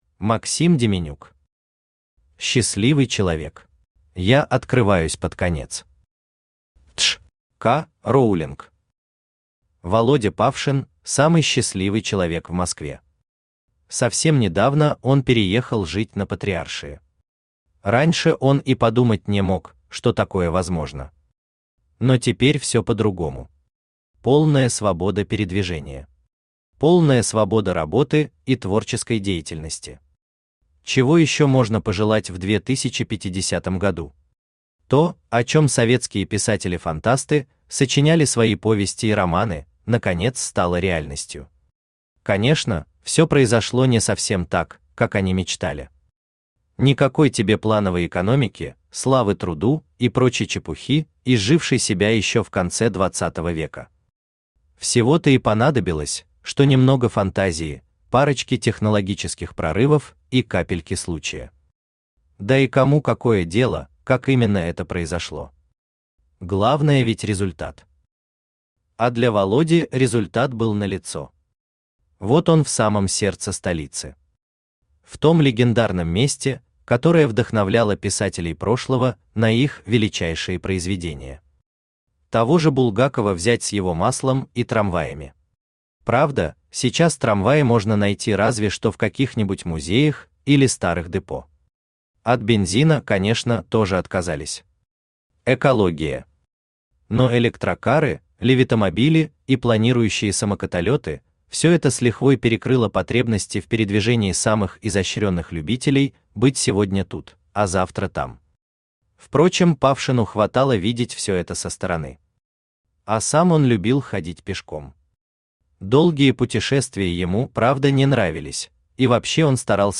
Аудиокнига Счастливый человек | Библиотека аудиокниг